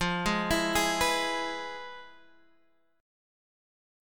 FmM7b5 chord